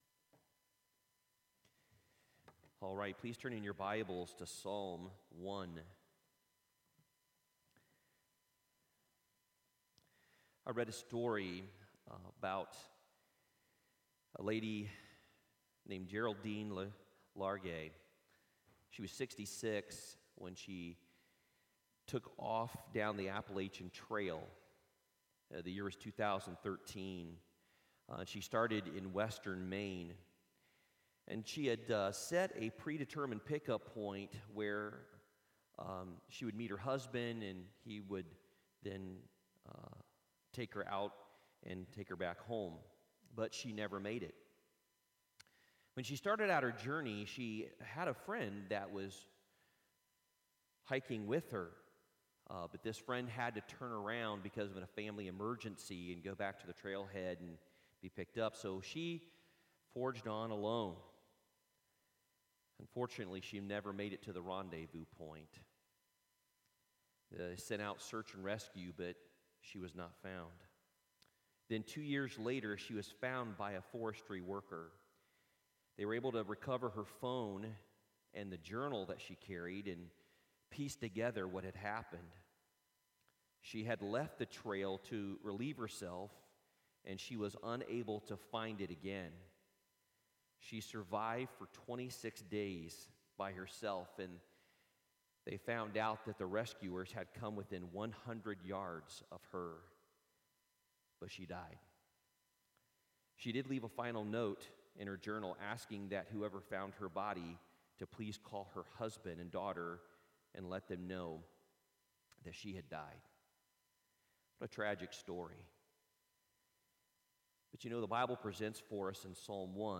Worship Service 11/15/2020